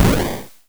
bakuhatu01.wav